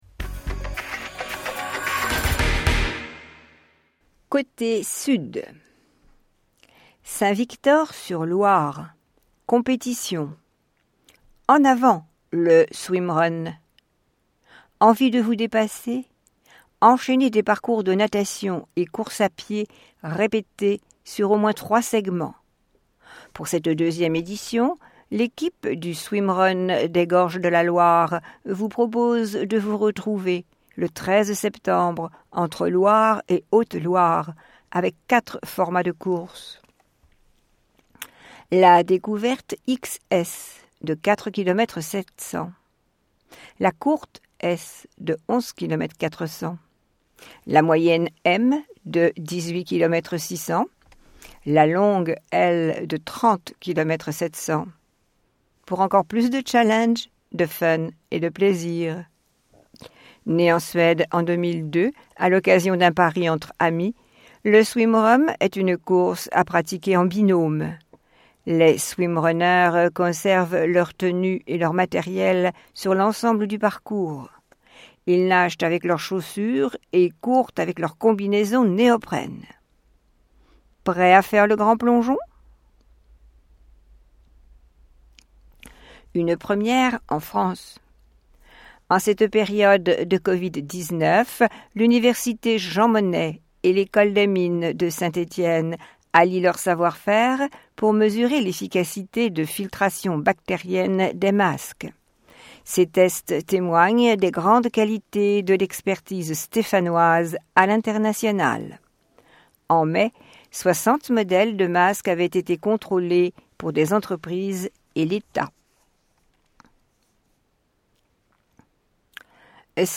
Loire Magazine n°142 version sonore